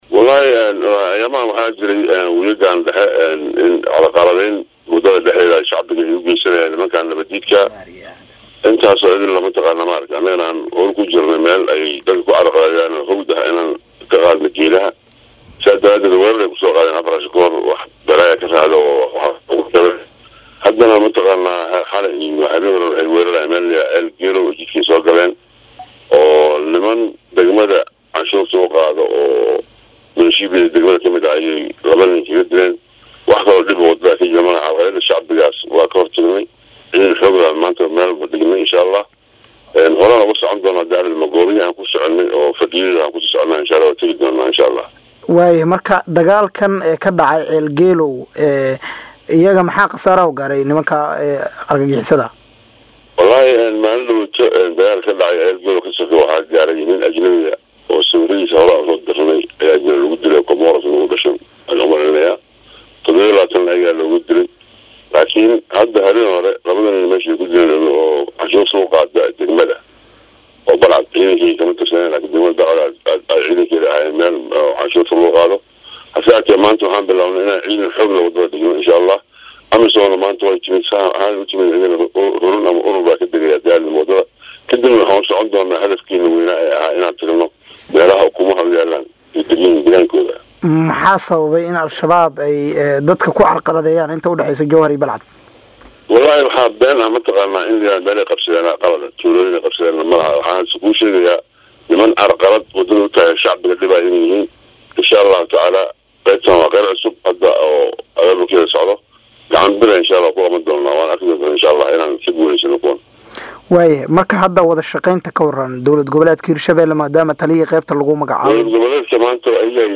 Waraysi-Gen-Koronto-Taliyaha-Qeybta-27-aad-ee-Hirsjhabeelle-.mp3